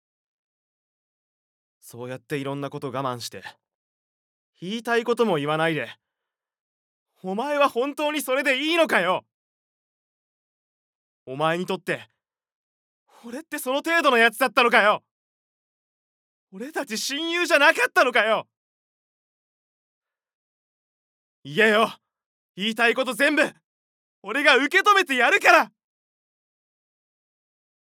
ボイスサンプル
熱血男子